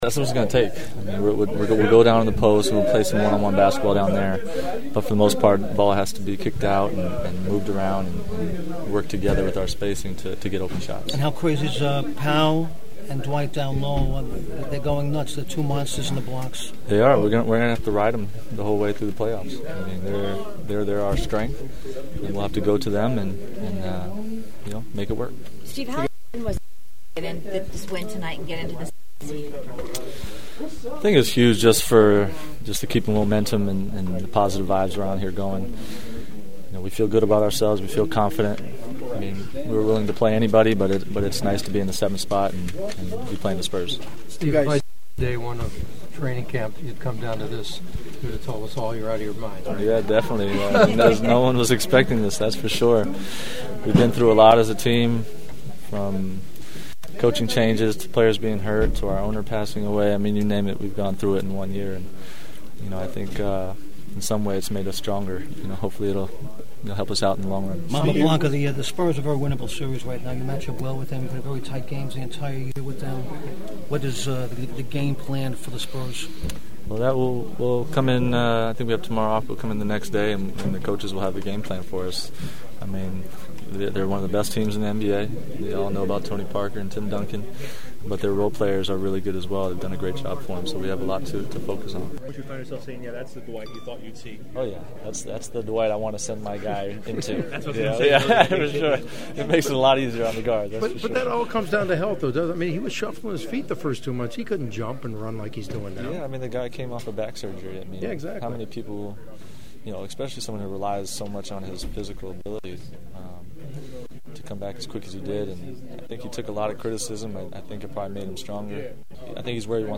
I had some great postgame locker room chats that unfortunately I won’t be able to share with you since they were messed up by a microphone malfunction.